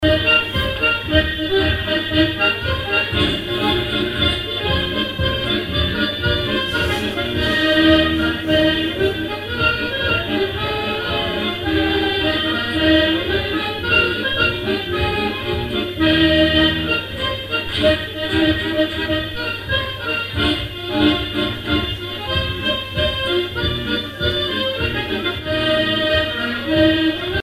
Mémoires et Patrimoines vivants - RaddO est une base de données d'archives iconographiques et sonores.
danse : polka des bébés ou badoise
airs pour animer un bal
Pièce musicale inédite